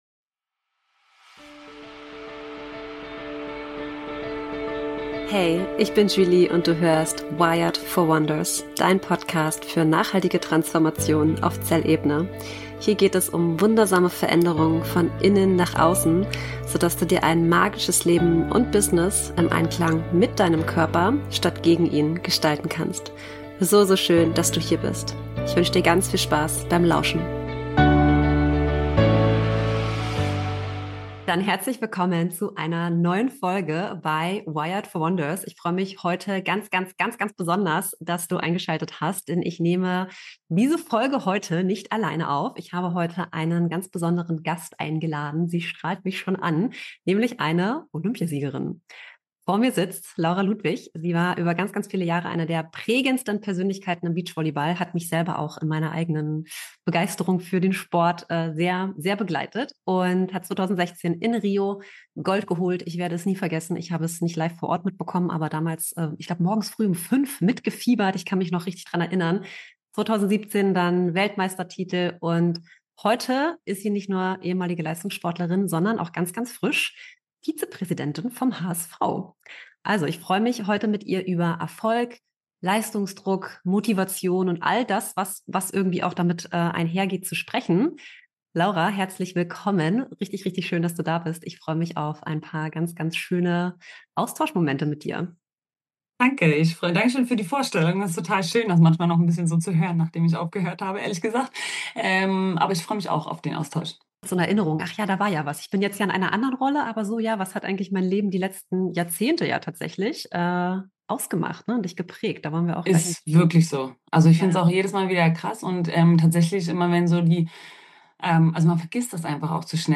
In dieser besonderen Folge habe ich einen ganz besonderen Interviewgast: Laura Ludwig, Olympiasiegerin im Beachvolleyball, Leistungssportlerin und Vizepräsidentin beim HSV. Sie teilt mit uns ihre Reise, ihren Erfolg und die vielen wertvollen Lektionen, die sie auf ihrem Weg als Leistungssportlerin gelernt hat.